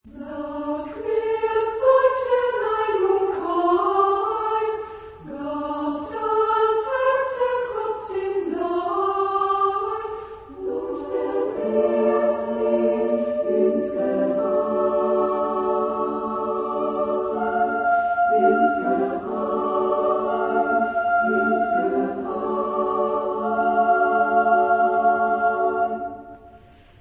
Genre-Style-Forme : Profane ; Lied
Type de choeur : SAA (div)  (3 voix égales de femmes )